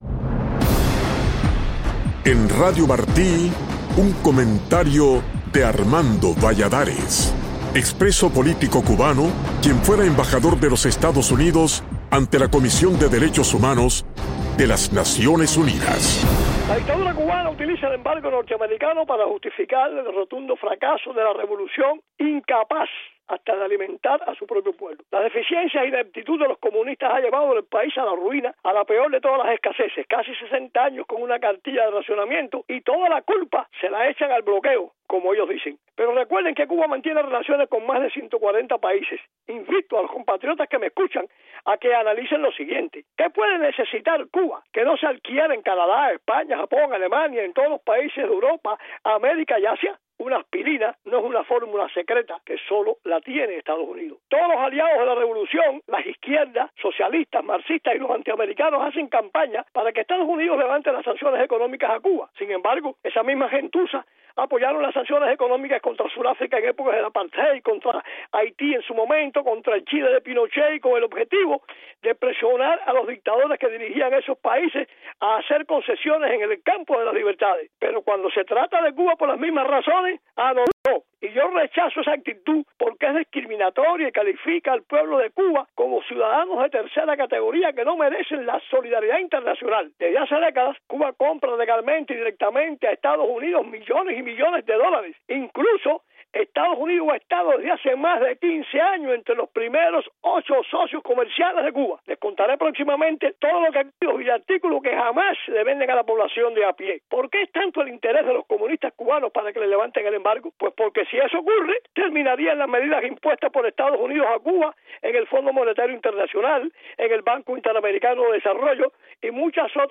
Pero el embajador Valladares nos recuerda en su comentario de hoy que Cuba mantiene relaciones con más de 140 países.